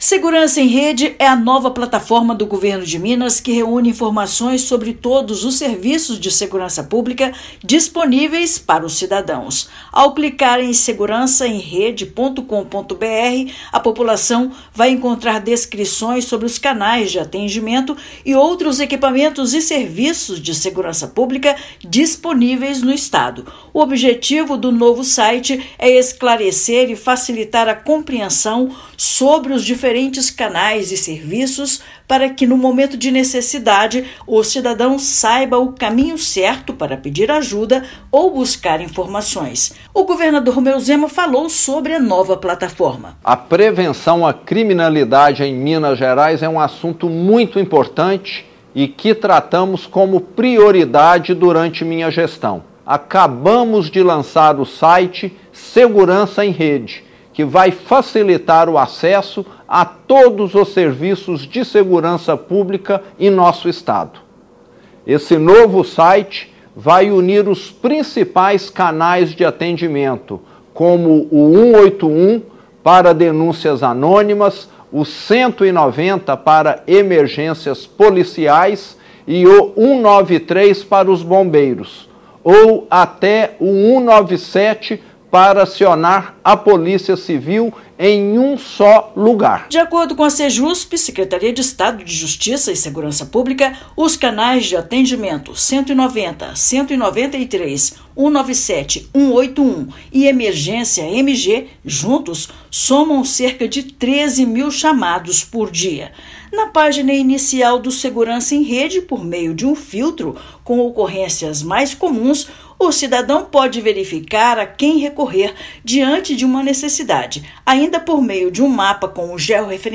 Iniciativa busca facilitar o acionamento e a compreensão sobre os diferentes canais de atendimento na área. Ouça matéria de rádio.